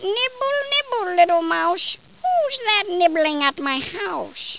00750_Sound_nibble.mp3